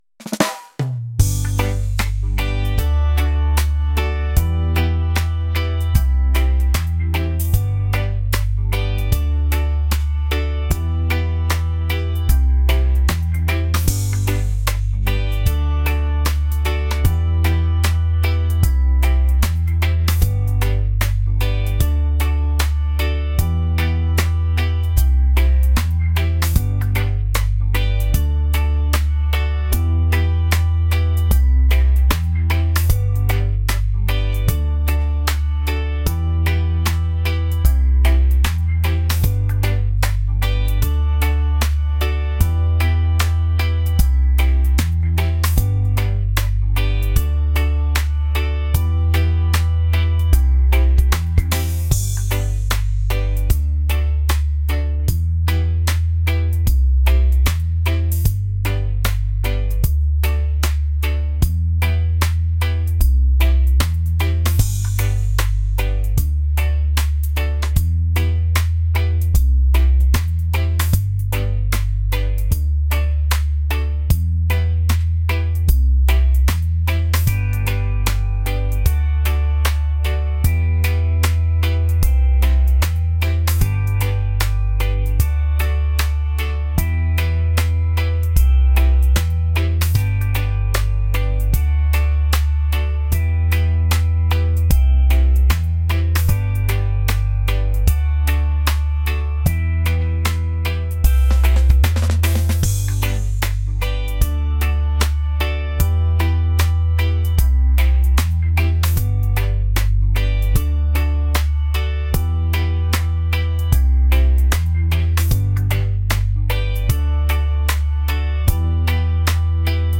laid-back | smooth | reggae